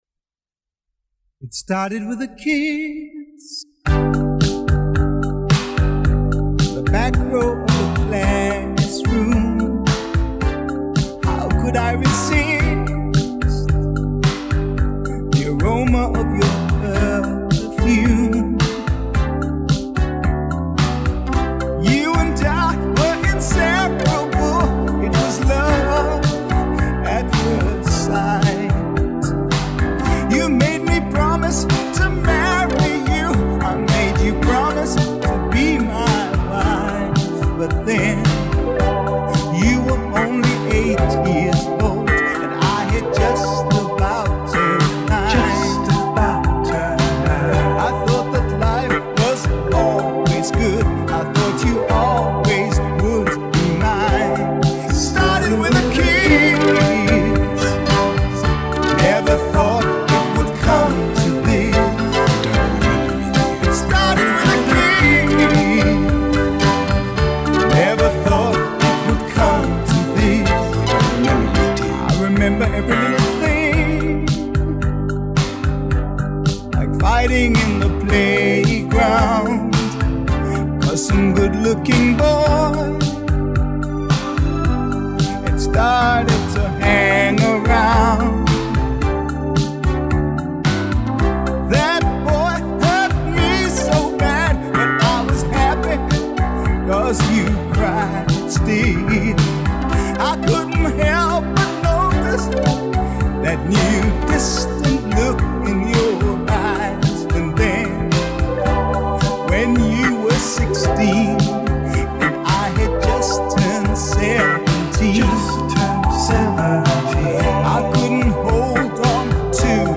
стили фанк и соул с элементами поп-рока и регги